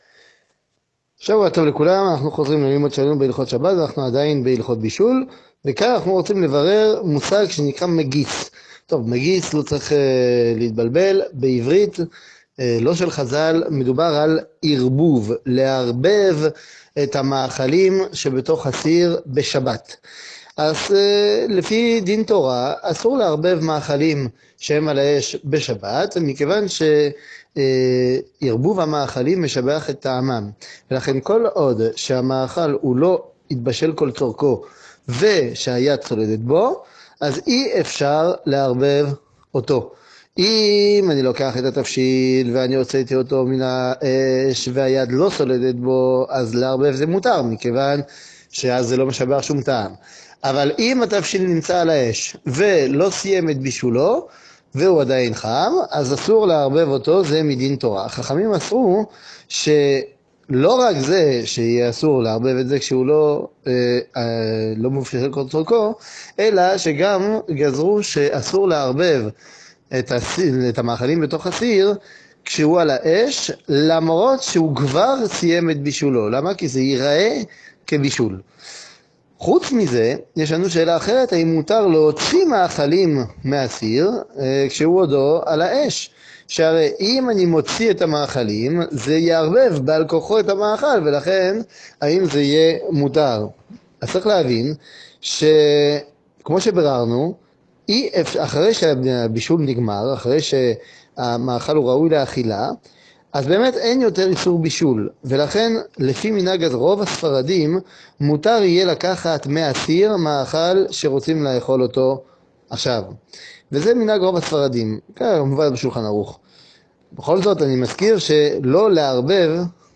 שיעורים